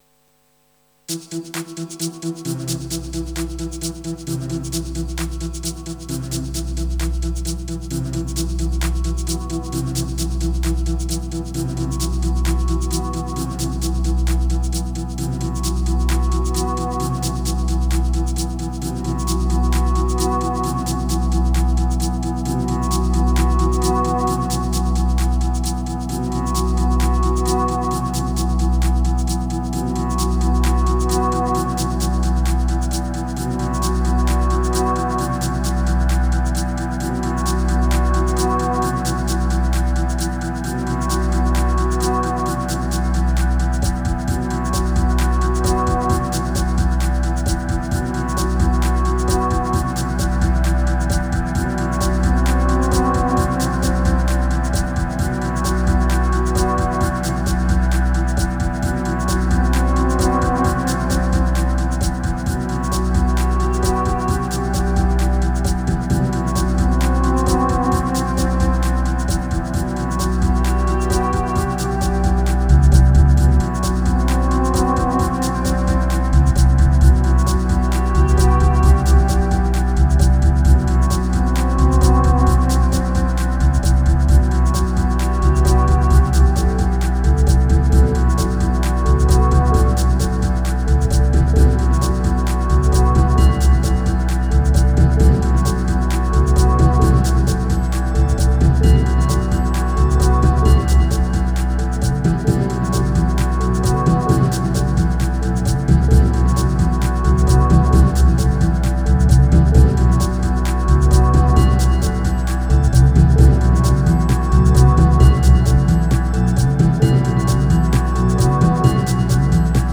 737📈 - 68%🤔 - 66BPM🔊 - 2021-02-21📅 - 391🌟